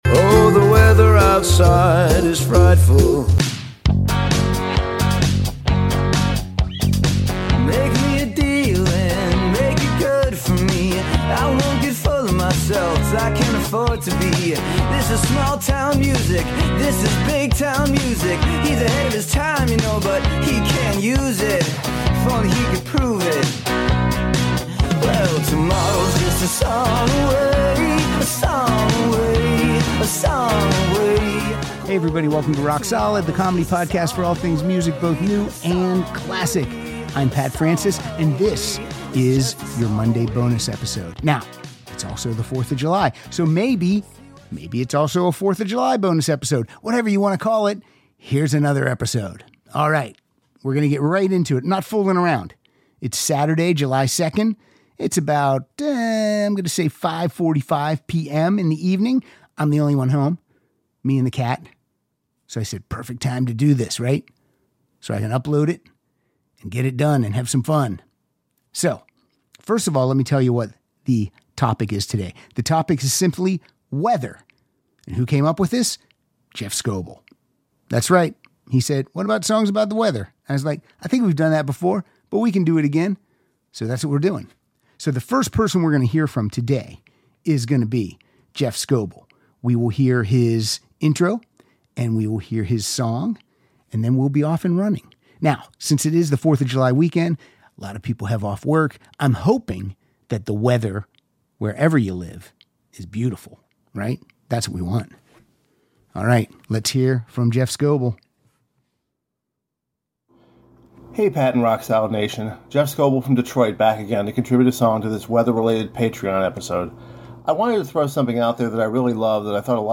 plays weather themed songs that were submitted by our Patreon supporters!